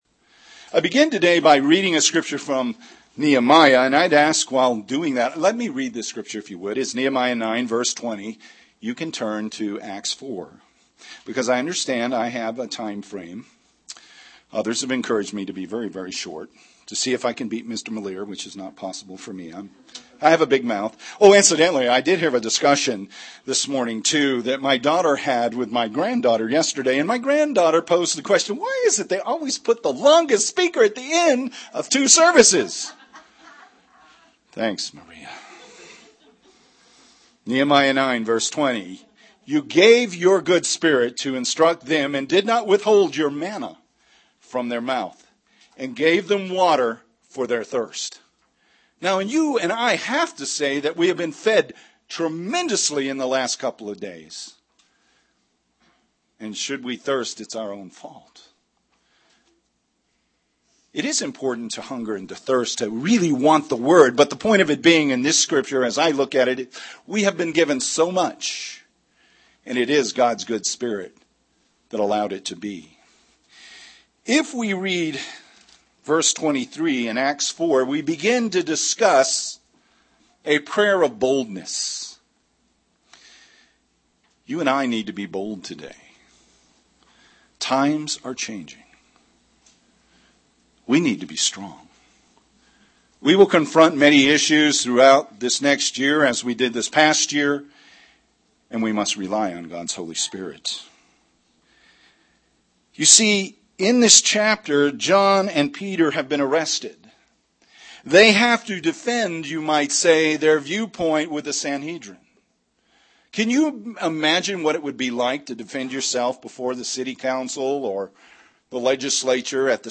Given in Albuquerque, NM
UCG Sermon Studying the bible?